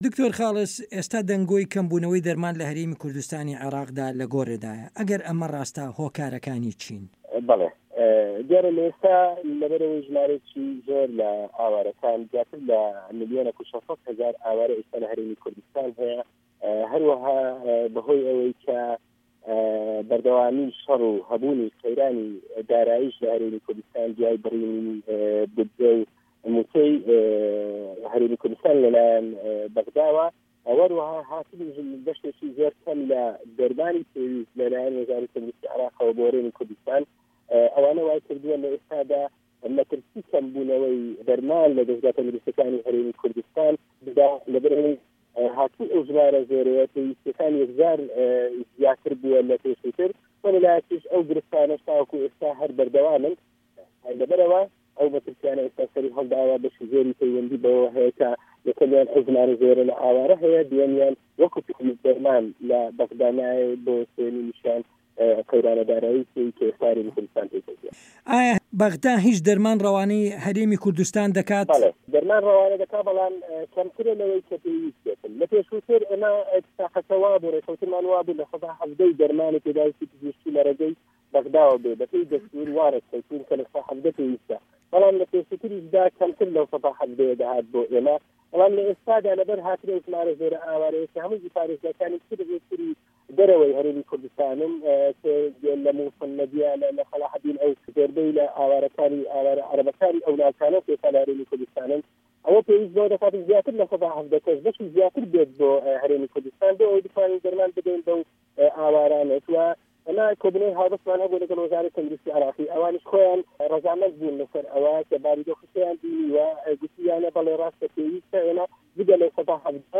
وتوێژ